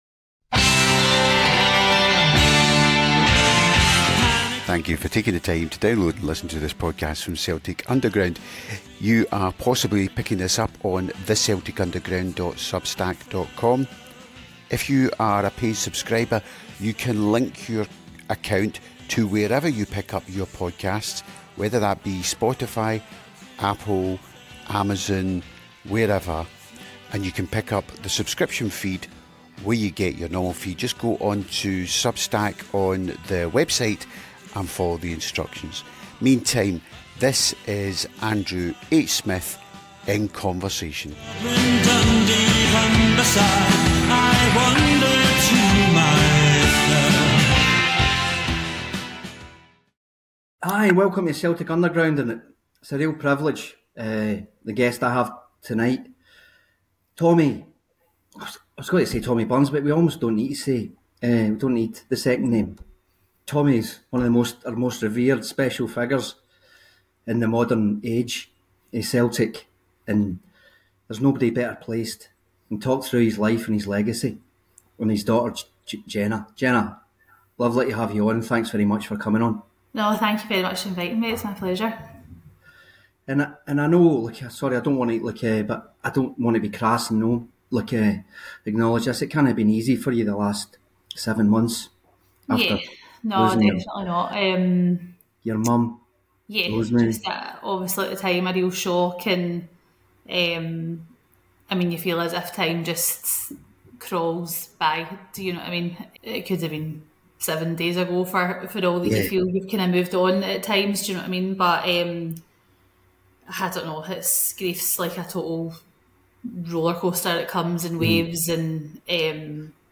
It’s quite an emotional discussion and one that will be appreciated by anyone who has lost their parents.